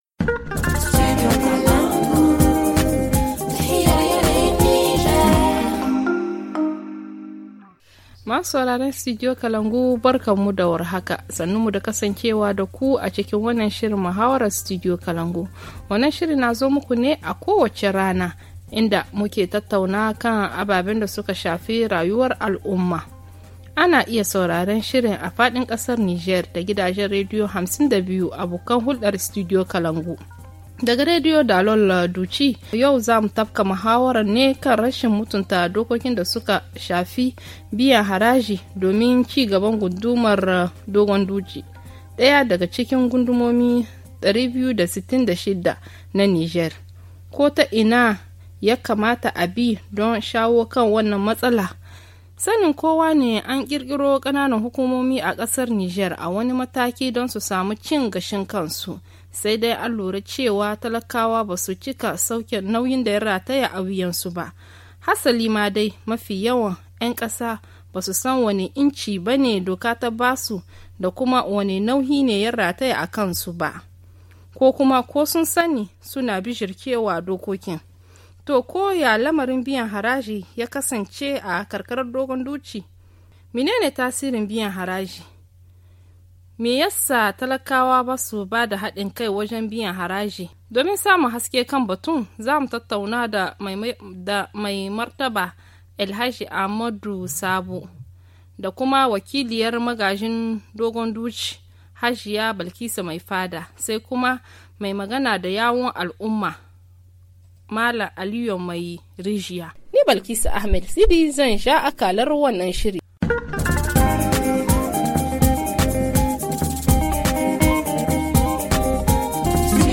HA Le forum en haoussa https